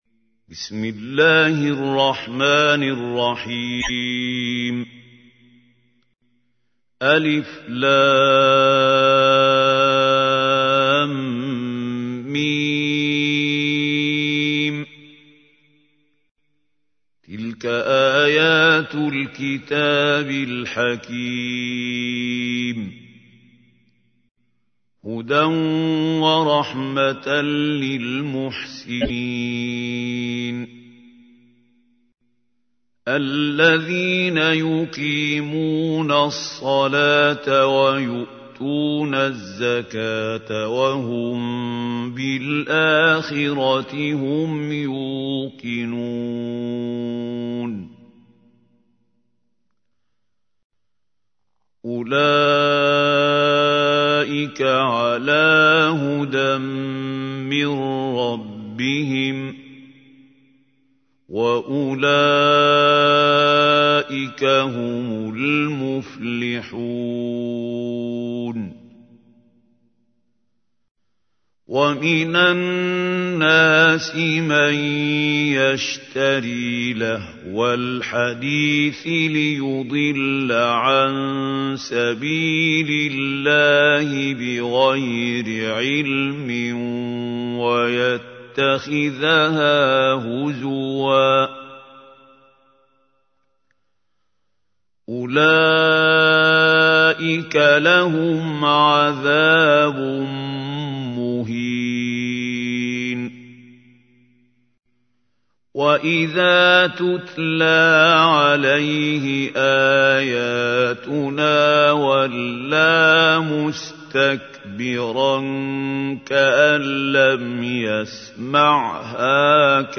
تحميل : 31. سورة لقمان / القارئ محمود خليل الحصري / القرآن الكريم / موقع يا حسين